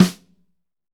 Index of /90_sSampleCDs/Northstar - Drumscapes Roland/SNR_Snares 2/SNR_P_C Snares x